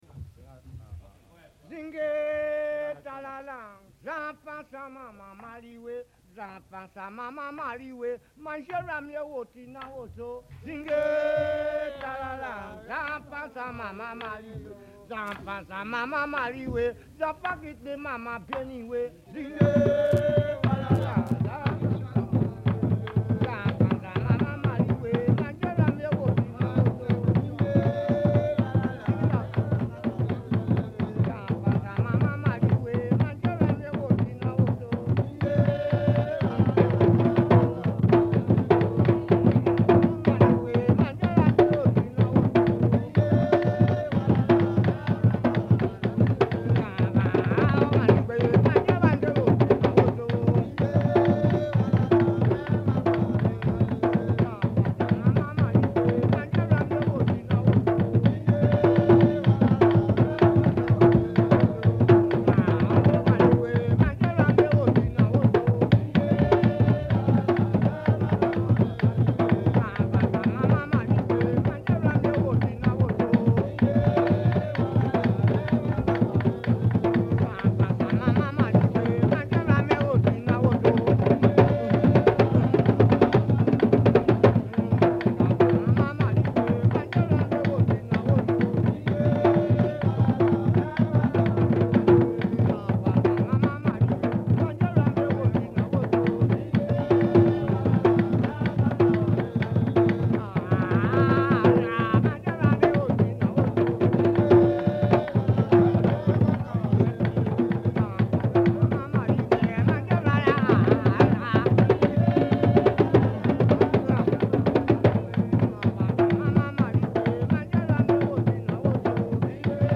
Inspelningarna är gjorda på fältet
Kalinda  Singay ta la, här sjunger man ”Sjung ta la, stick hem till morsan för jag har kommit för att slå ner dig”.
kalinda.mp3